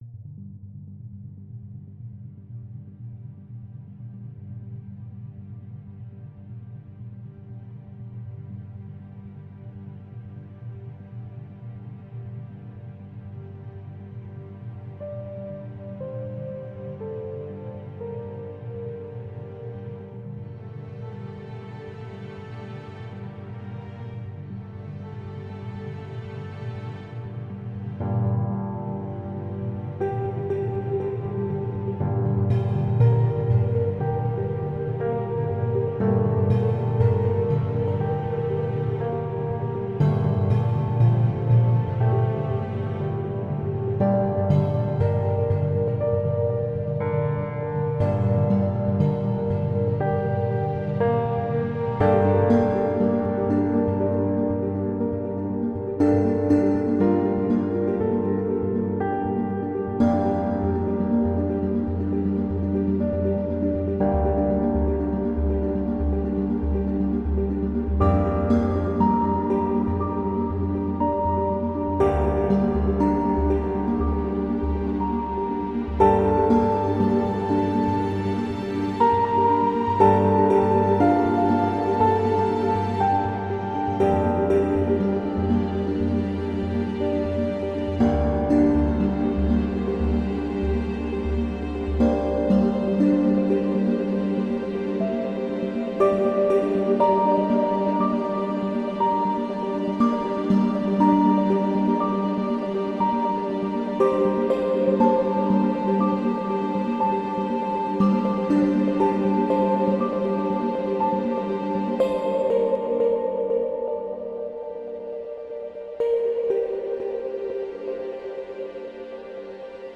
Мотивирующая фоновая музыка для вдохновения